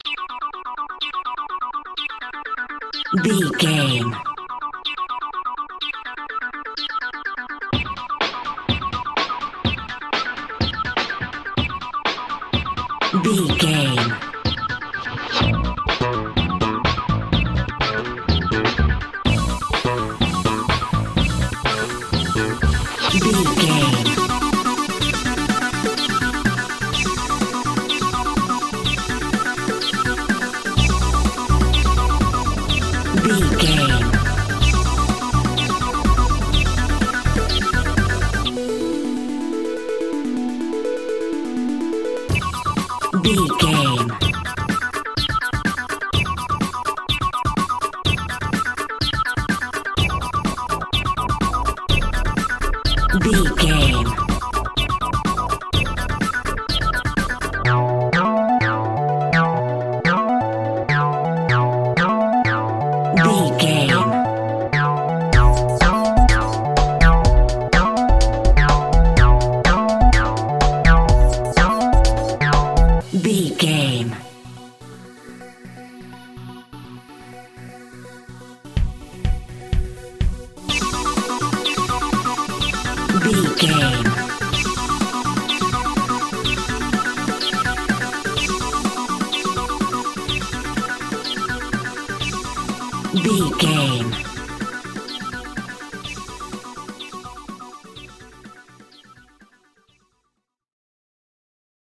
Aeolian/Minor
Fast
uplifting
lively
futuristic
hypnotic
industrial
synthesiser
bass guitar
drum machine
house
techno
electro
synth drums
synth leads
synth bass